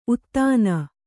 ♪ uttāna